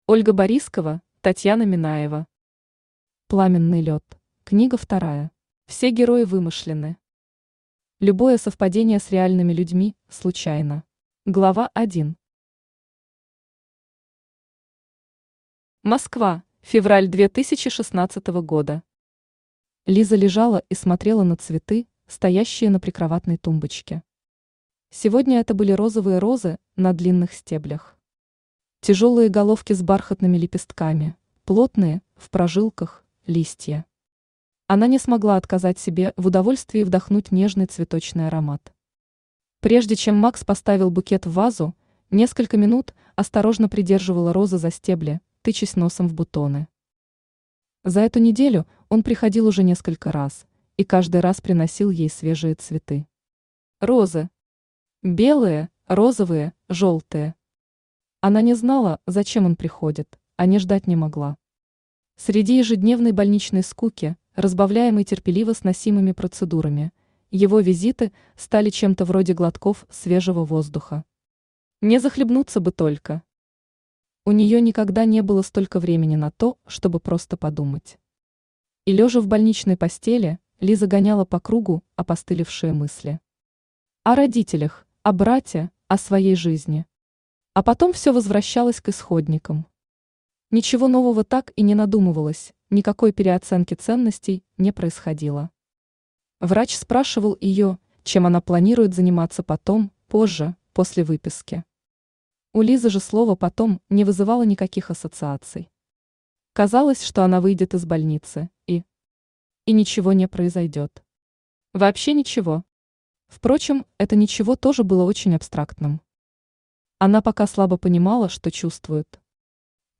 Аудиокнига Пламенный лёд. Книга вторая | Библиотека аудиокниг
Книга вторая Автор Ольга Борискова Читает аудиокнигу Авточтец ЛитРес.